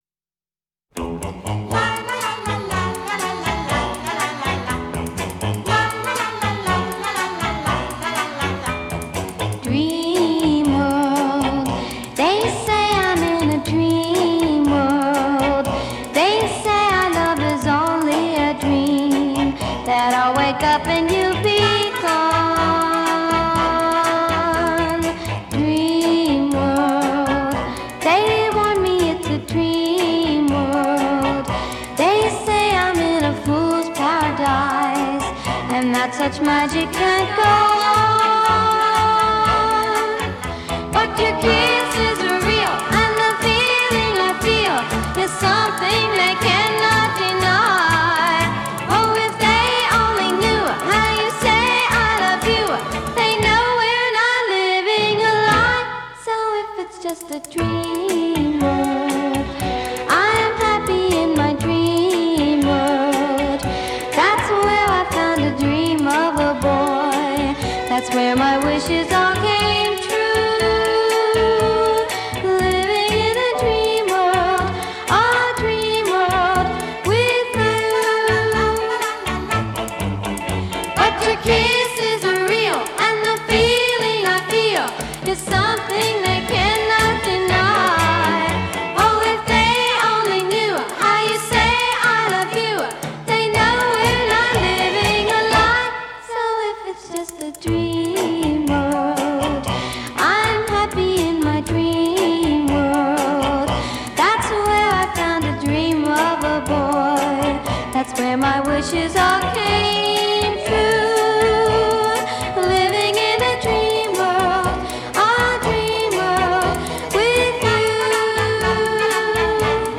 Genre: Pop, Rock & Roll, Beat